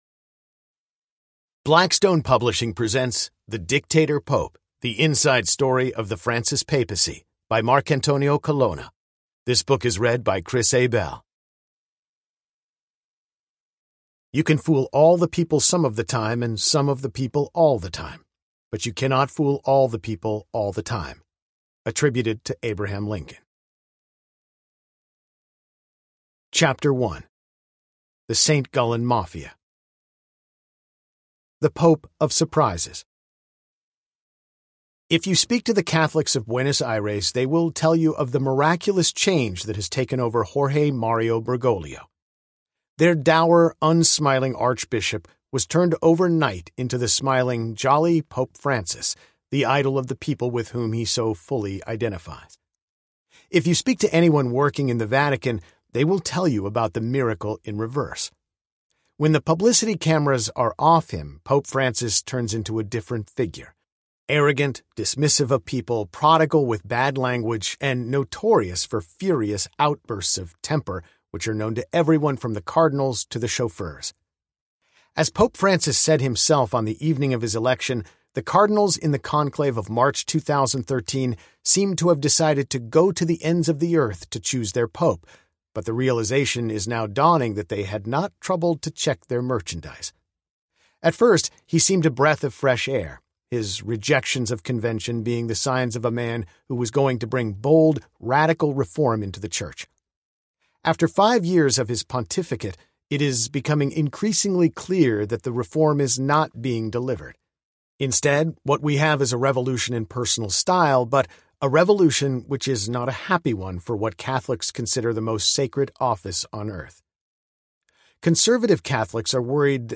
The Dictator Pope Audiobook
Narrator
– Unabridged